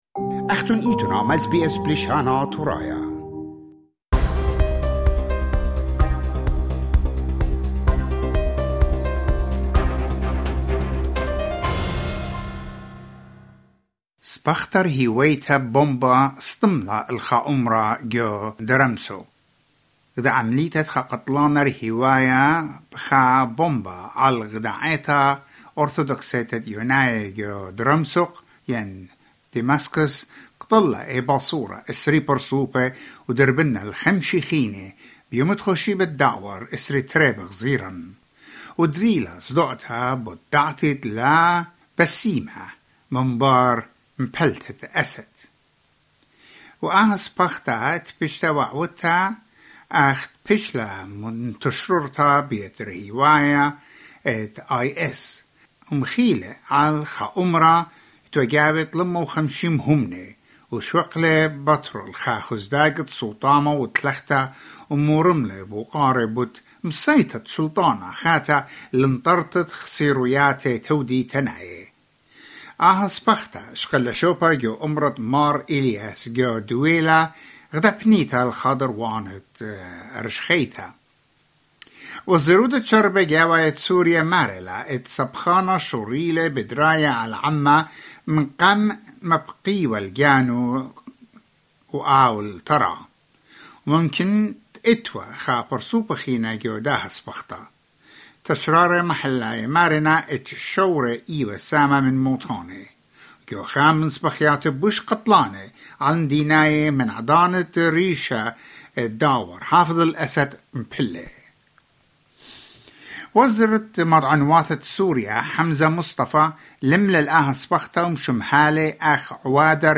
Click on play to listen to the interview. Click on play to listen to the report.